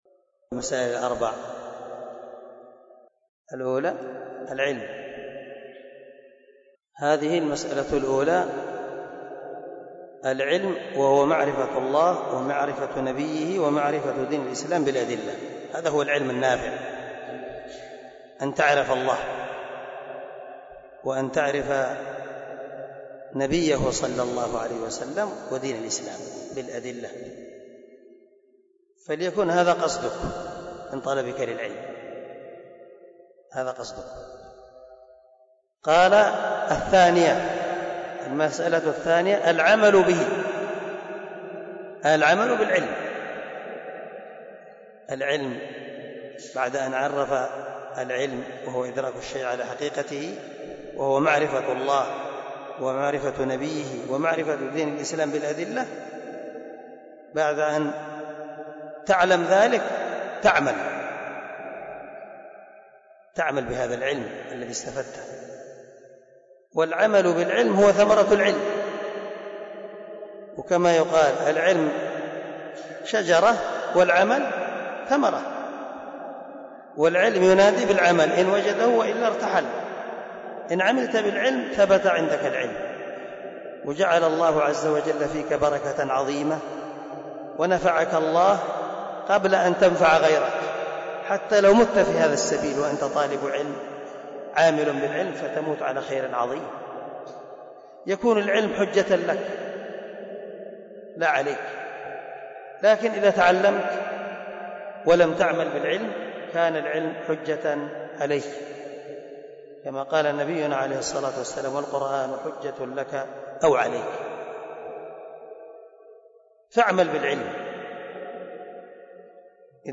🔊 الدرس 2 من شرح الأصول الثلاثة